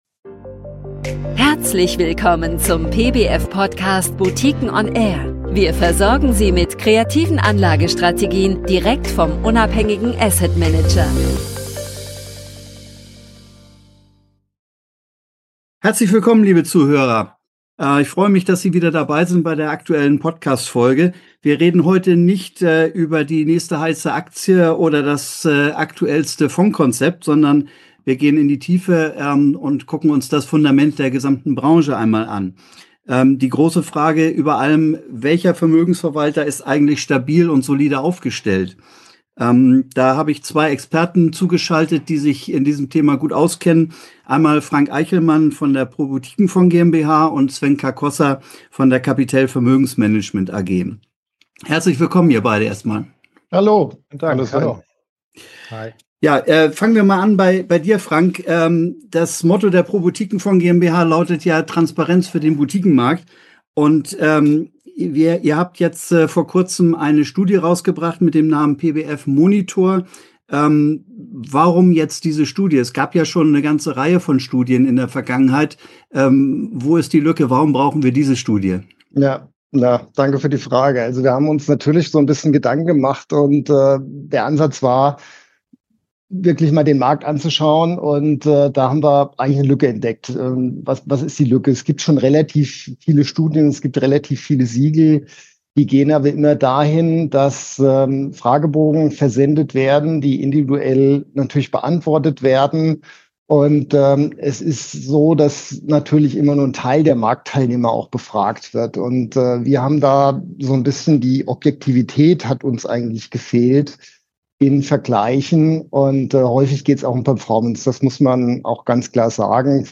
Im Podcast-Gespräch